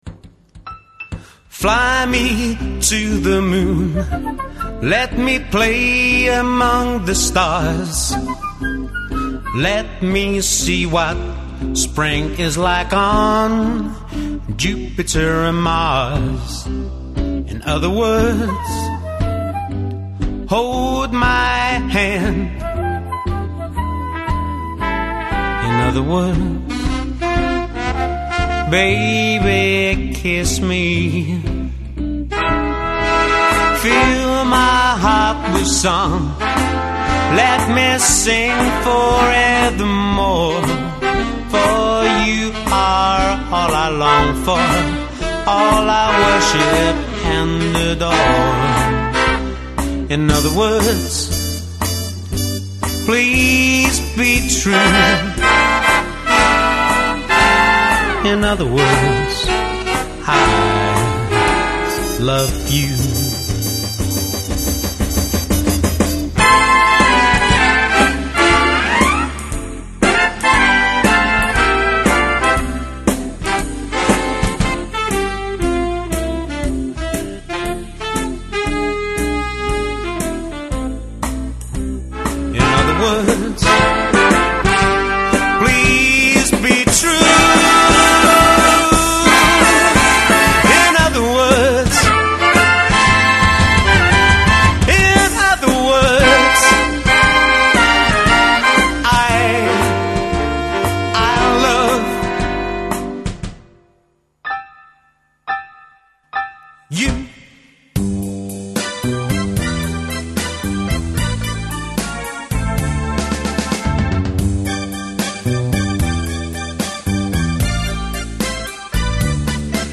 Swing, Jazz, Motown
Classic big band songs
laid back swing